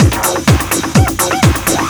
DS 126-BPM B1.wav